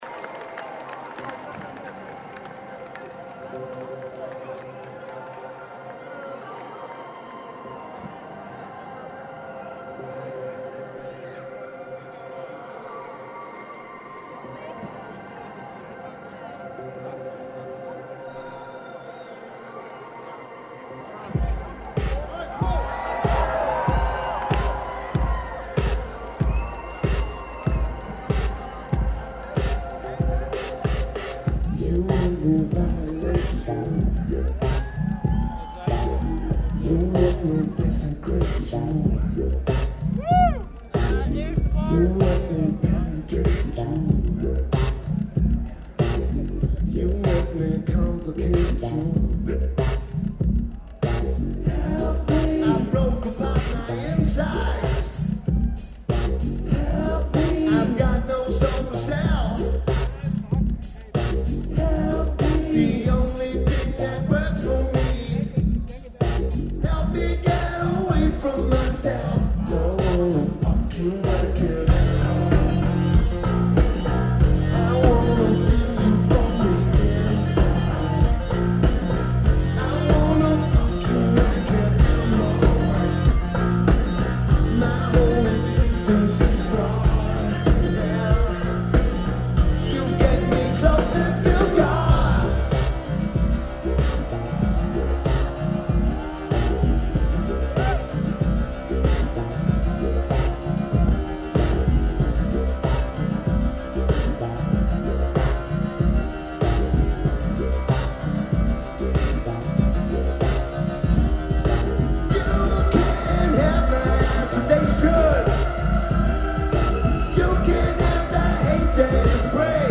AFAS Live
Amsterdam Netherlands
Lineage: Audio - AUD (Olympus LS-5 + Internal Mics)